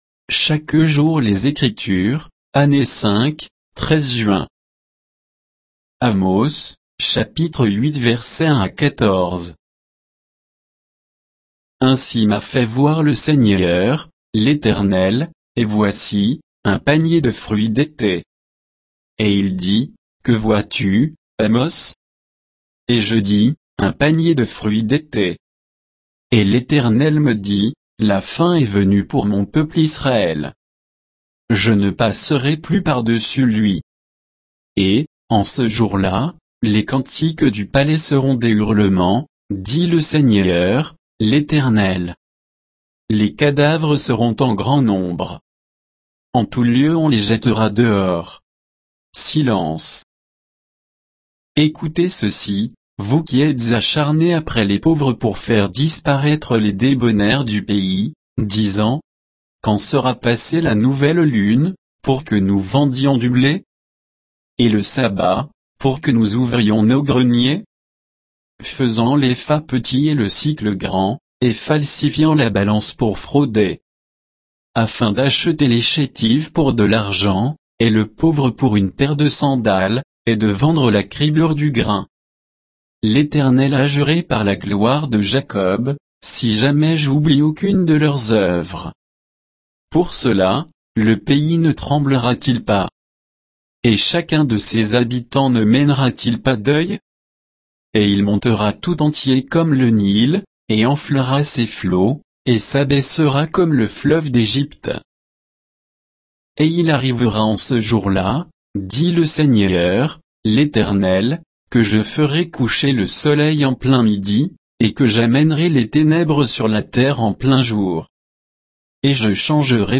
Méditation quoditienne de Chaque jour les Écritures sur Amos 8